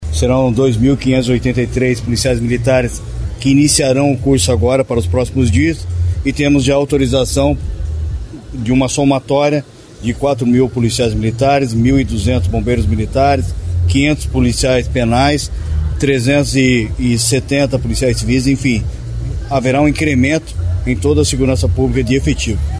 Sonora do secretário Estadual da Segurança Pública, Hudson Teixeira, sobre a divulgação do resultado do concurso público para a PMPR